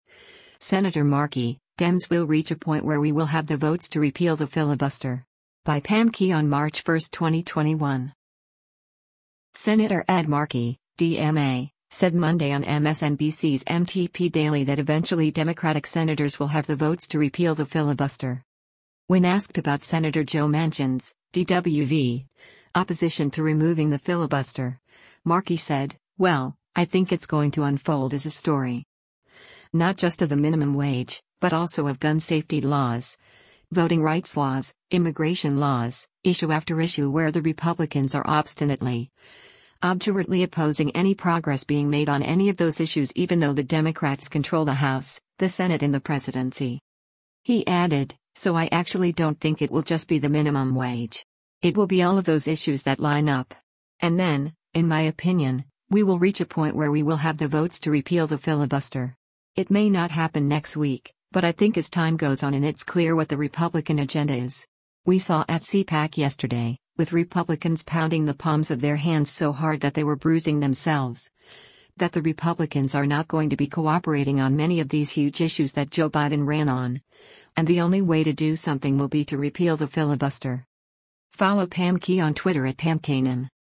Senator Ed Markey (D-MA) said Monday on MSNBC’s “MTP Daily” that eventually Democratic Senators will have the votes to “repeal the filibuster.”